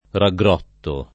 raggrotto [ ra gg r 0 tto ]